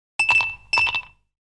ice-cube-sound.mp3